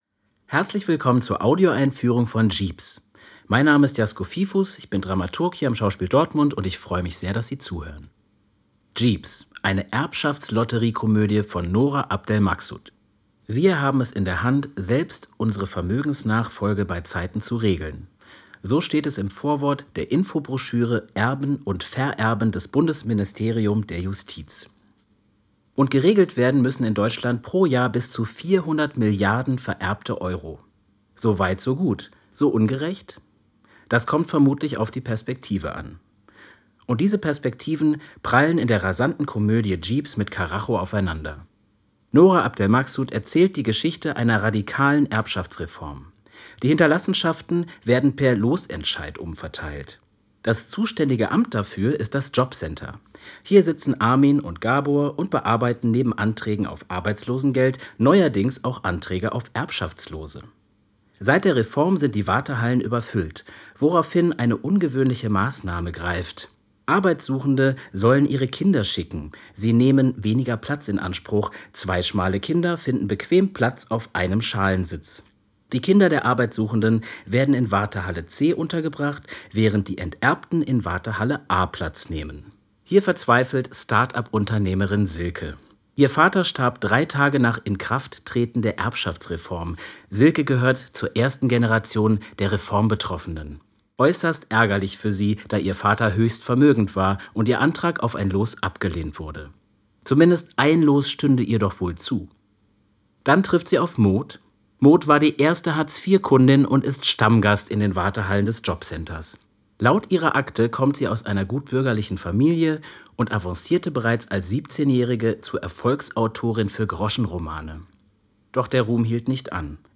tdo_einfuehrung_jeeps.mp3